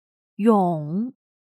勇/yǒng/corajudo; valiente
勇.mp3